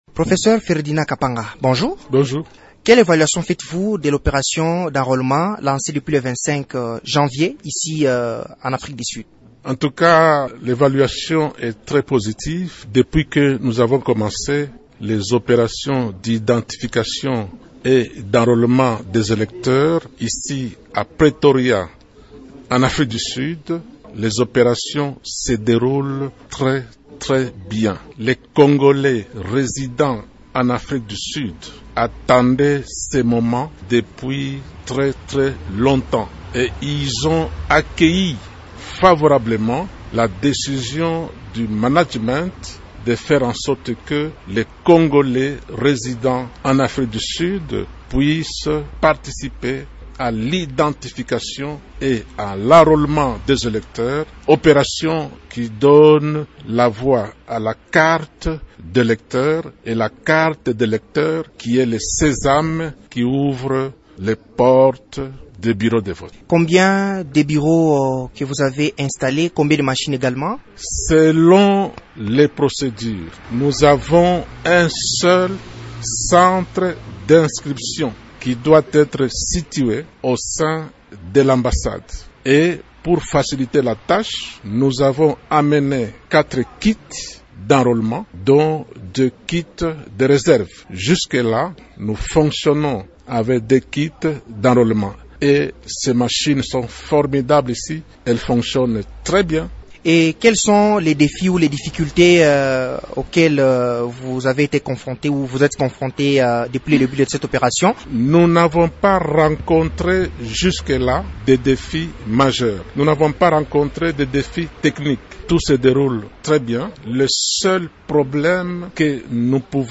Dans une interview accordée à Radio Okapi ce lundi 6 fevrier